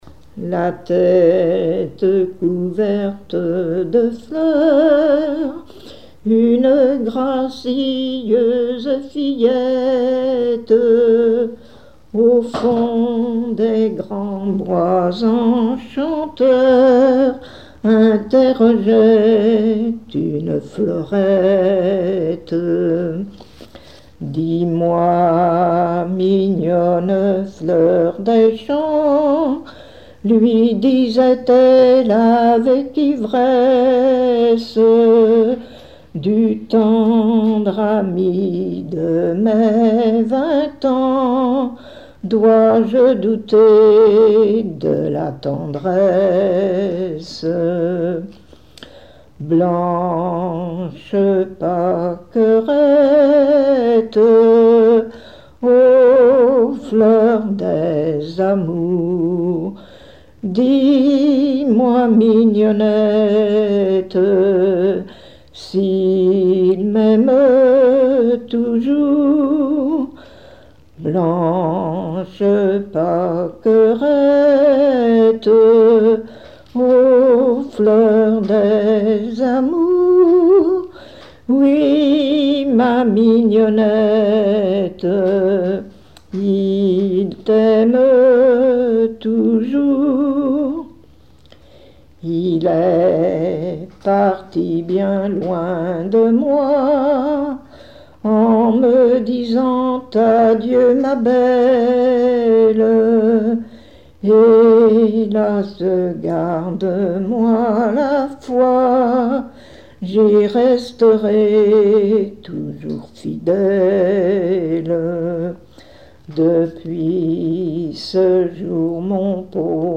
Genre strophique
Chansons populaires et traditionnelles
Pièce musicale inédite